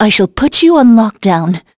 Veronica-Vox_Skill7.wav